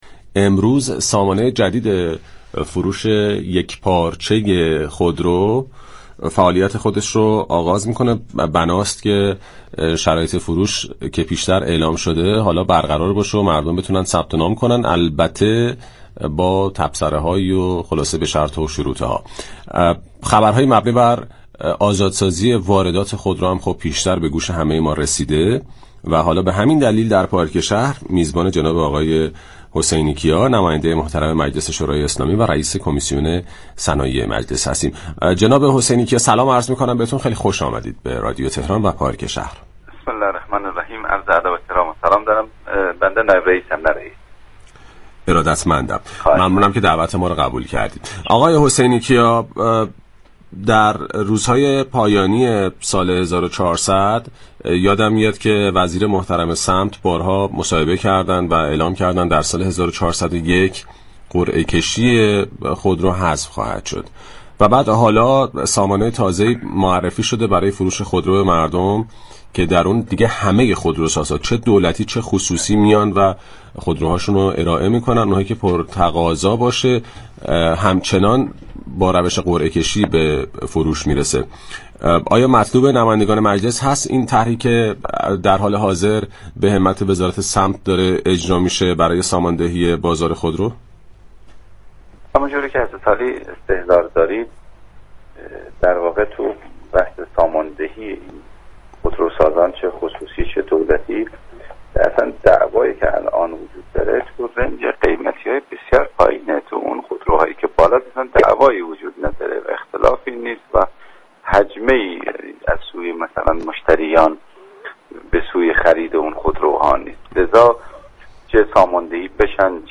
به گزارش پایگاه اطلاع رسانی رادیو تهران، سیدجواد حسینی‌كیا نائب رئیس كمیسیون صنایع و معادن مجلس شورای اسلامی در گفتگو با پارك شهر رادیو تهران درخصوص سامانه یكپارچه فروش خودرو، گفت:خودروهایی كه عامه مردم مطالبه می‌كنند بیشتر خودروهای ارزان قیمت دو خودورساز سایپا و ایران خودرو است.